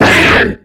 Cri_0696_XY.ogg